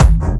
VEC3 Bassdrums Dirty 10.wav